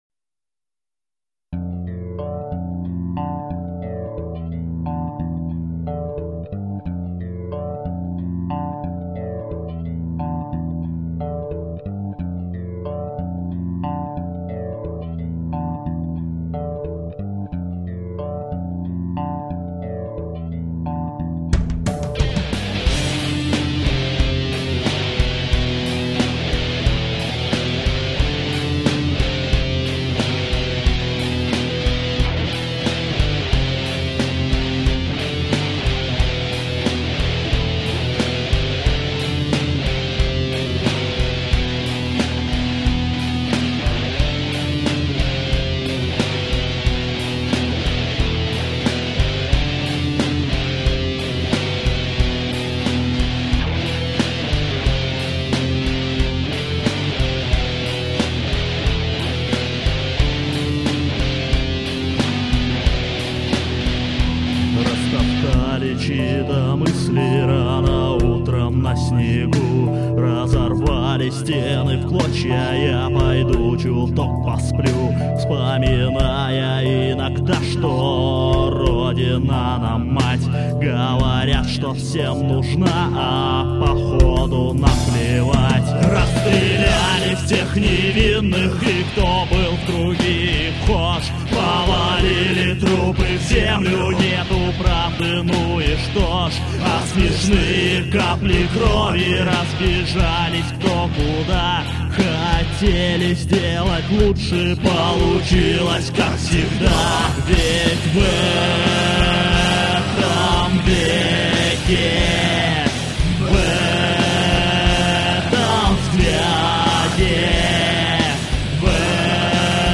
• Жанр: Альтернативная